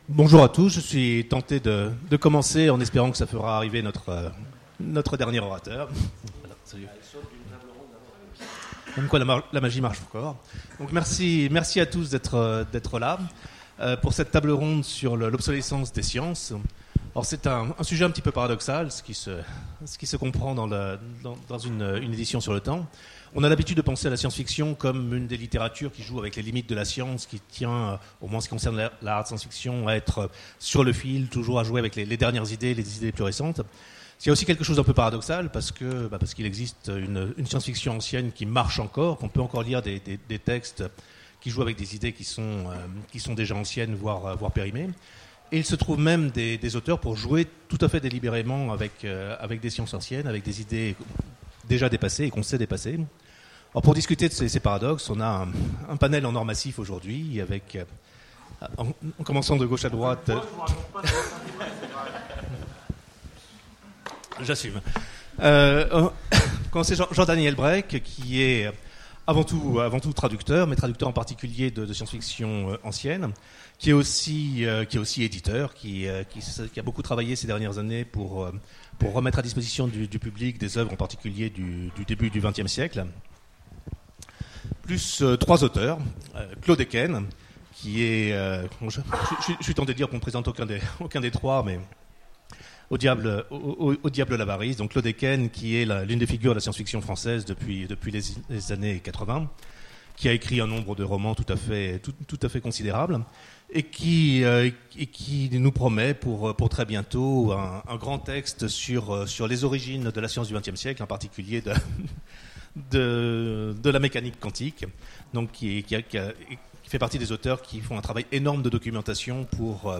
Utopiales 2017 : Conférence L’obsolescence des sciences dans la littérature et vice versa